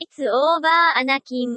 Tags: Star Wars japanese dub